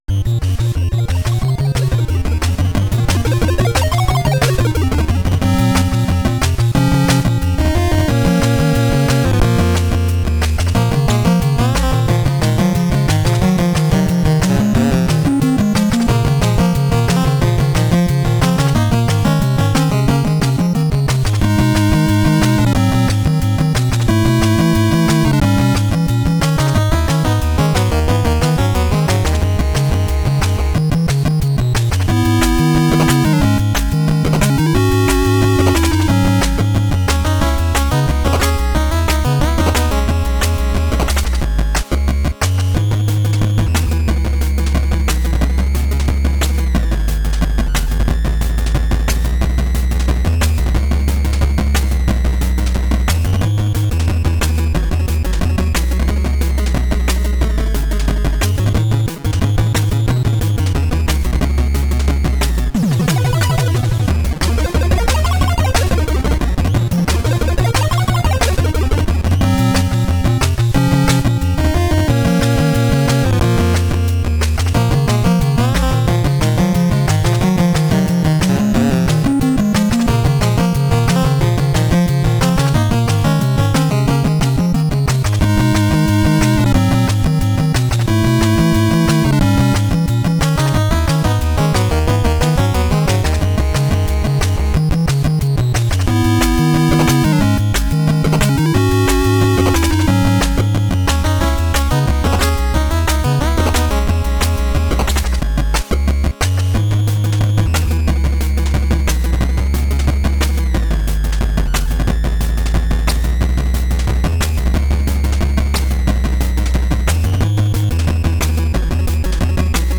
A simple NES tune.